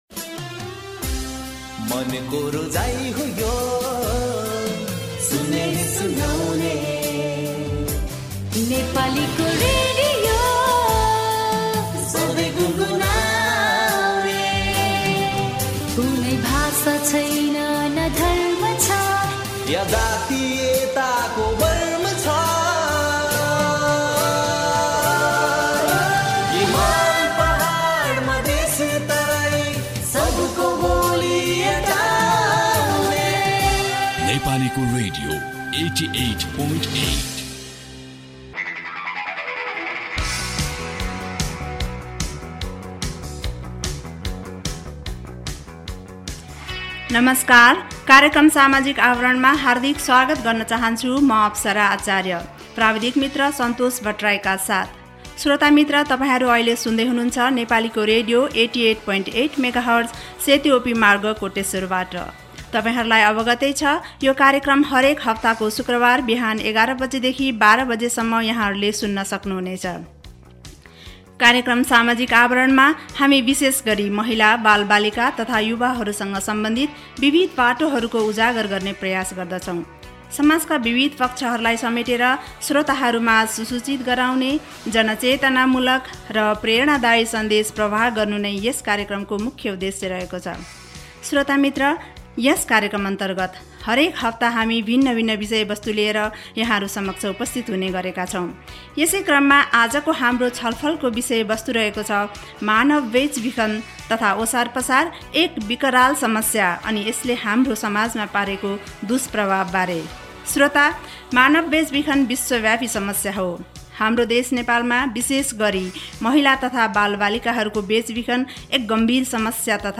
Radio Programme Samajik Awaran